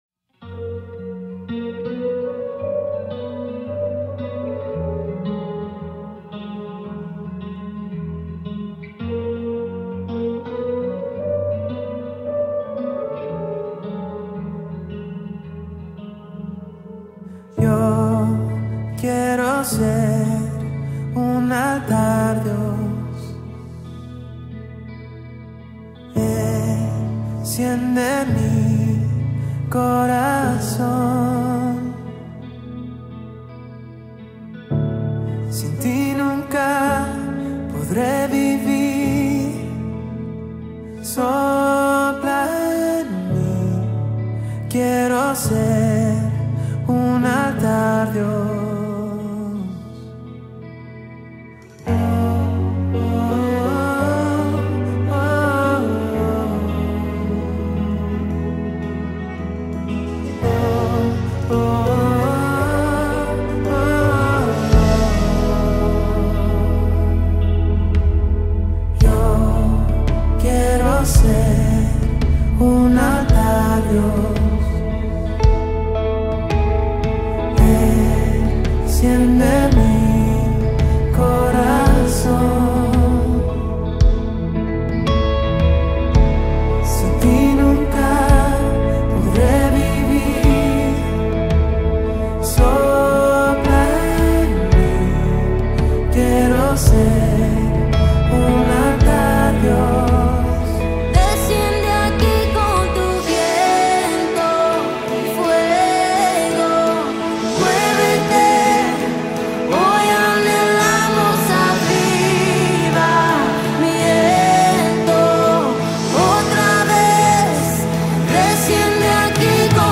146 просмотров 54 прослушивания 1 скачиваний BPM: 168